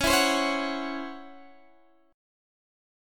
C#mM11 chord